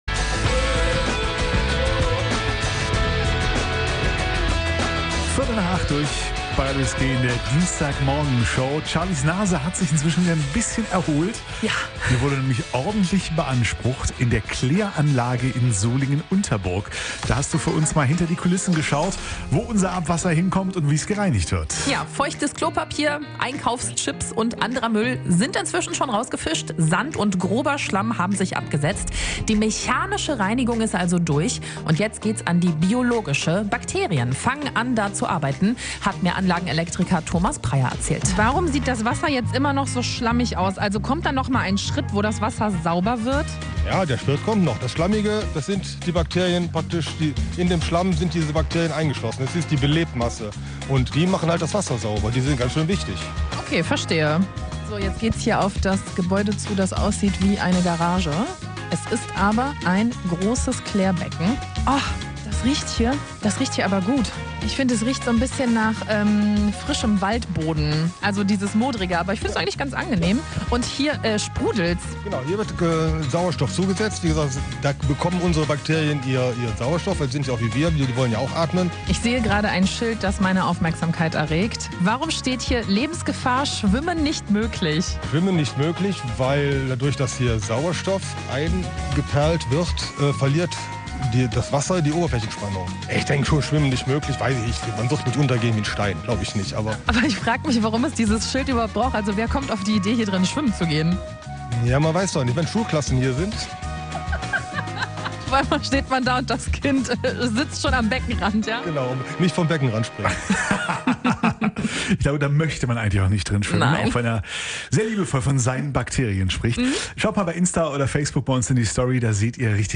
Reportage aus dem Klärwerk Solingen Burg - Teil 2